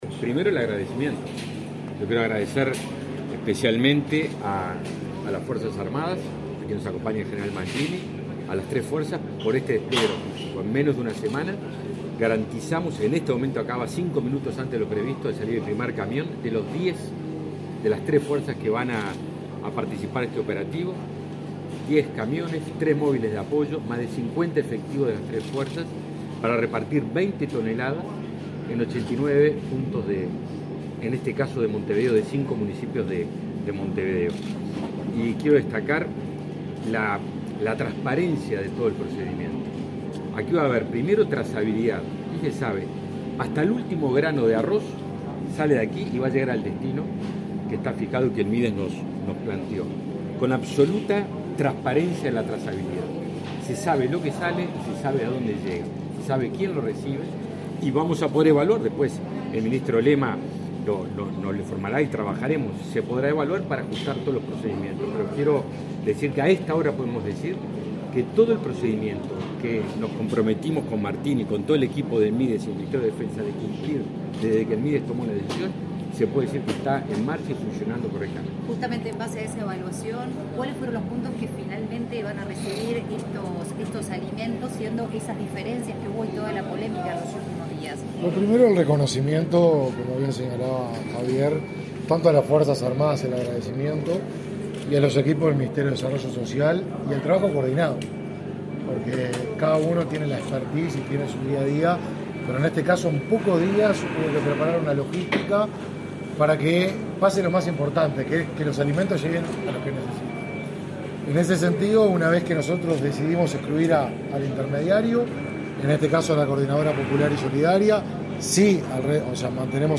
Declaraciones de los ministros Javier García y Martín Lema
El ministro de Defensa Nacional, Javier García, y su par de Desarrollo Social, Martín Lema, concurrieron este viernes 7 al centro de acopio de Uruguay